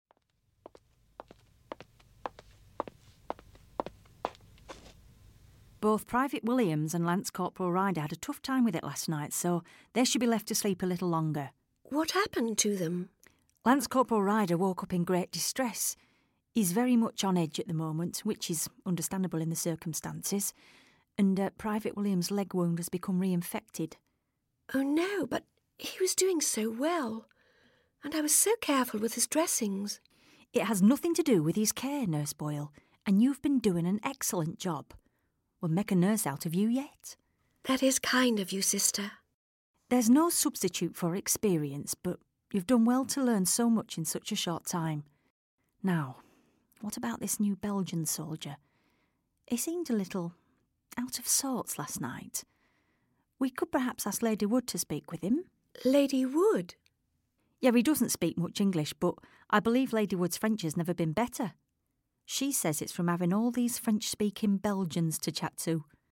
Two nurses at Temple Newsam hospital discuss their patients' progress.